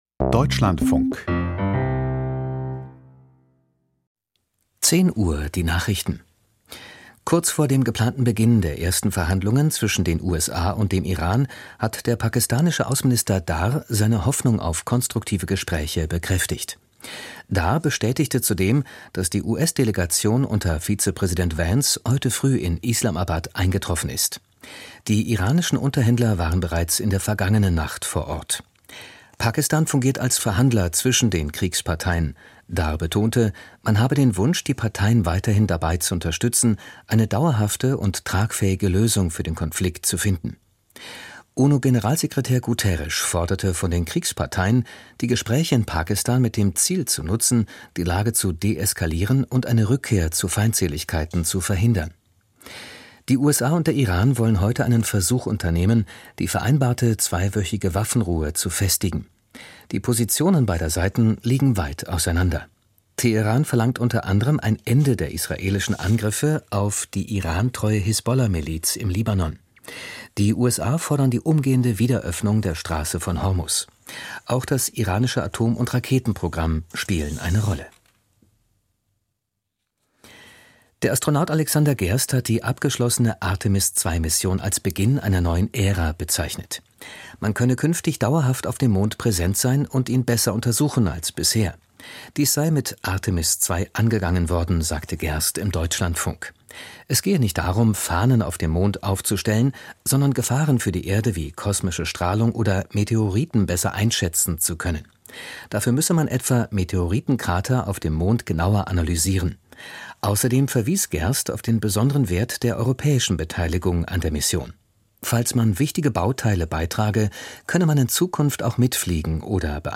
Die Nachrichten vom 11.04.2026, 10:00 Uhr
Die wichtigsten Nachrichten aus Deutschland und der Welt.